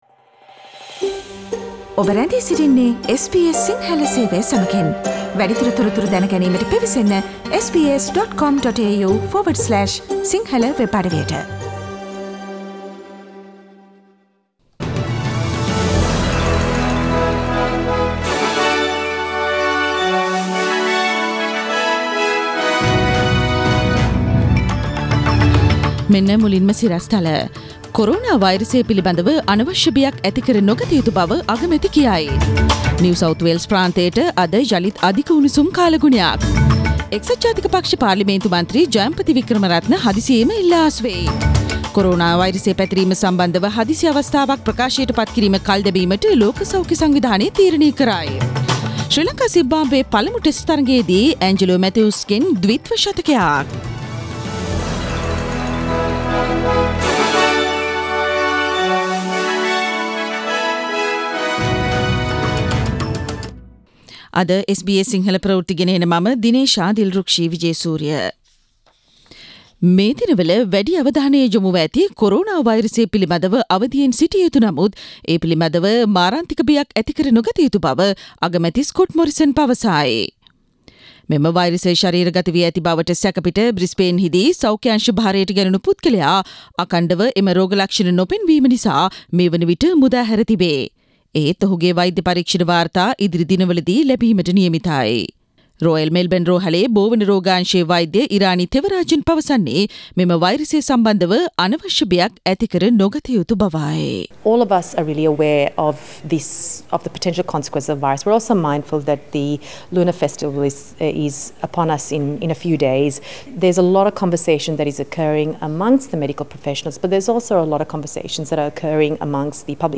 SBS Sinhala news Source: SBS Sinhala Radio